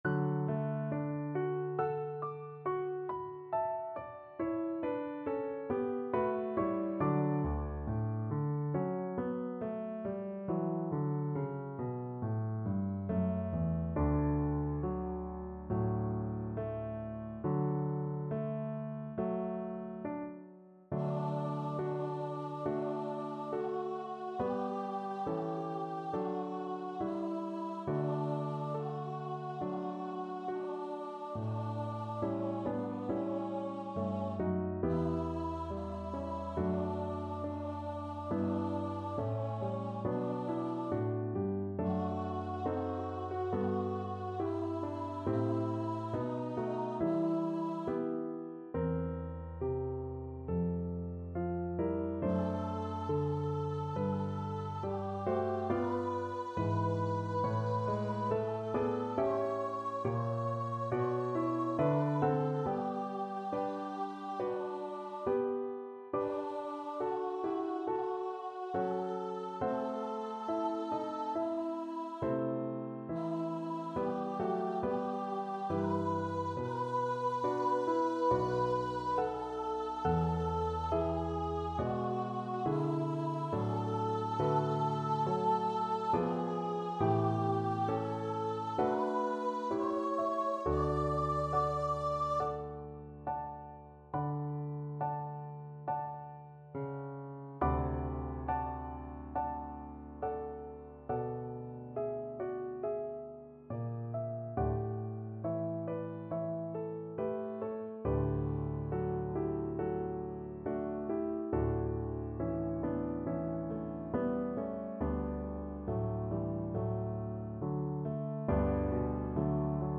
D major (Sounding Pitch) (View more D major Music for Vocal Duet )
Slow =c.69
2/2 (View more 2/2 Music)
Classical (View more Classical Vocal Duet Music)